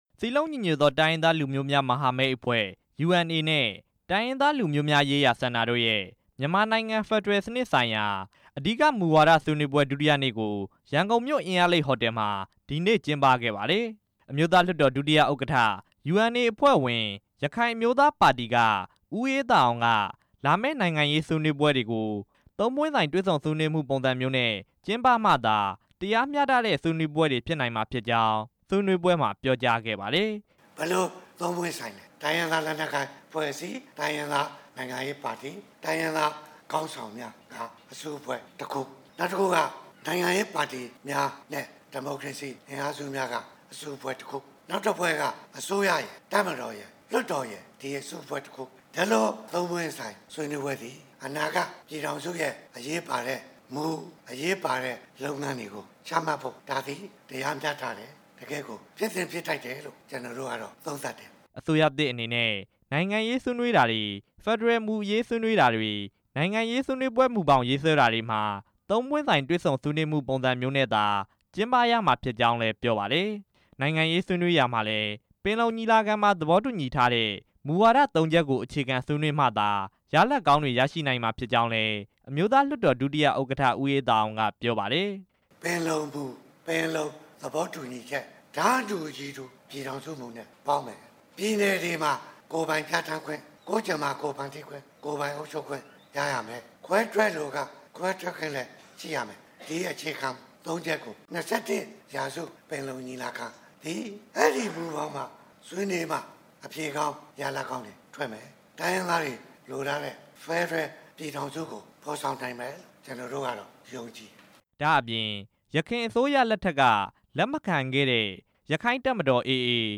၂၁ ရာ စုပင်လုံညီလာခံမှာ ပင်လုံမူ၊ ပင်လုံသဘောတူညီချက်တွေကို အခြေခံဆွေးနွေးမှသာ တိုင်ရင်းသားတွေလိုလားတဲ့ ဖက်ဒရယ်ပြည်ထောင်စု ကို ဖော်ဆောင်နိုင်မှာဖြစ်ကြောင်းနဲ့ တိုင်းရင်းသား လက်နက်ကိုင်အဖွဲ့အားလုံး ပါဝင်ဆွေးနွေးခွင့်ရဖို့ အရေးကြီးကြောင်း အမျိုးသားလွှတ်တော် ဒုတိယဥက္ကဋ္ဌ ဦးအေးသာအောင် က မြန်မာနိုင်ငံ ဖက်ဒရယ်စနစ်ဆိုင်ရာ မူဝါဒဆွေးနွေးပွဲမှာ ပြောလိုက်ပါတယ်။